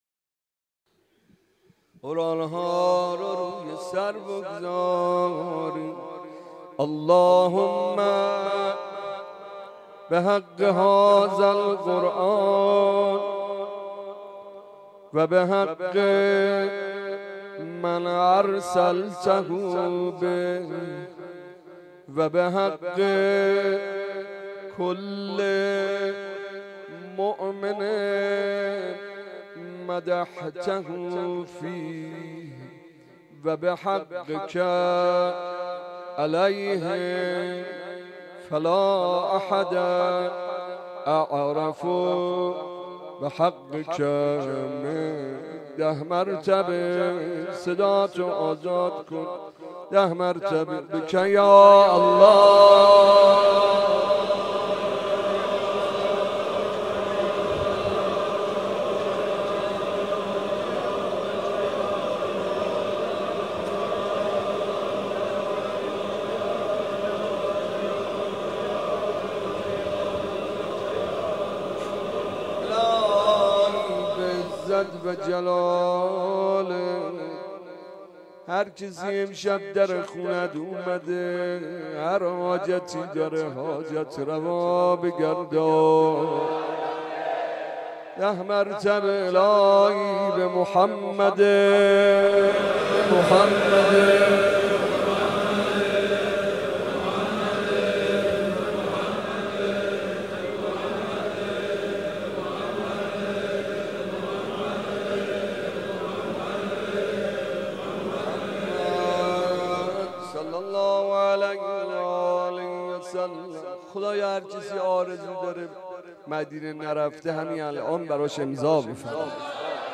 مراسم احیا
04.ghoran be sar.mp3